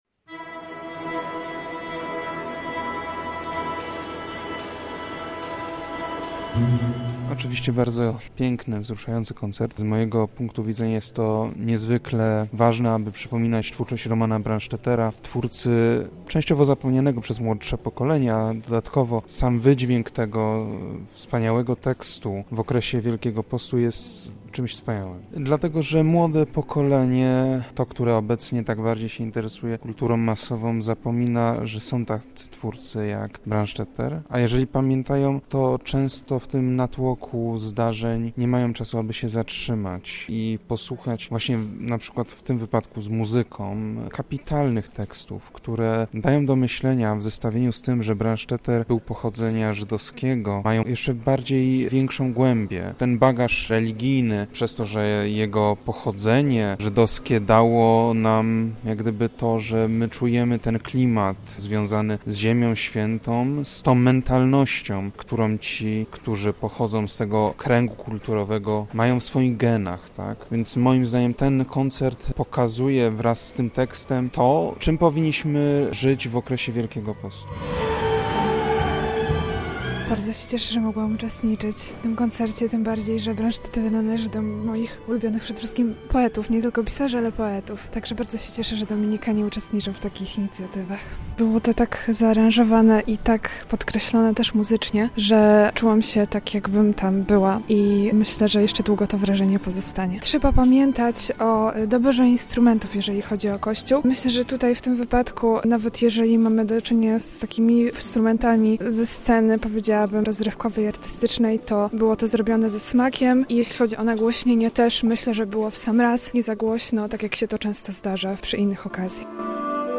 Spotkanie odbyło się w ramach cyklu Wielkopostne Śpiewanie, organizowanego przez lubelskich Dominikanów.
Powieść Brandstaettera „Jezus z Nazarethu” łączy 4 Ewangelie w jedną księgę. Dopełnia ją muzyka klezmerska.